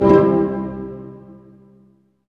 Index of /90_sSampleCDs/Roland L-CD702/VOL-1/HIT_Dynamic Orch/HIT_Tutti Hits